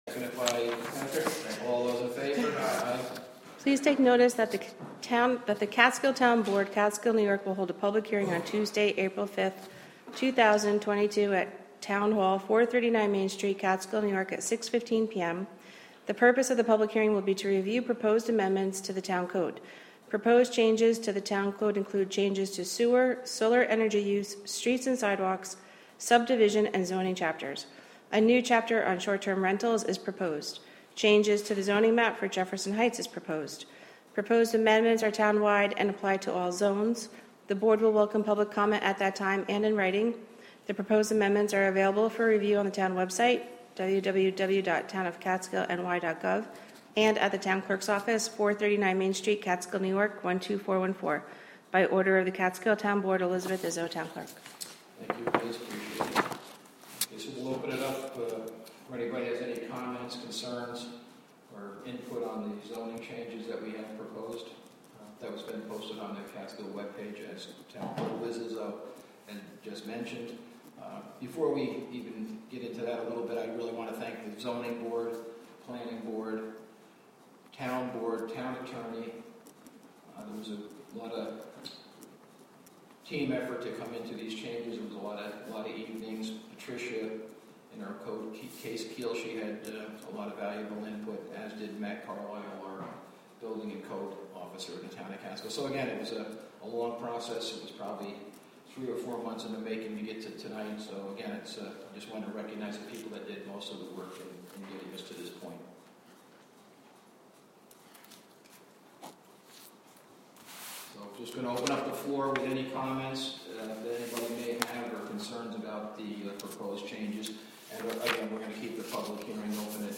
Live from the Town of Catskill: April Town Board Meeting (Audio)